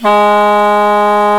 THAI PIPES02.wav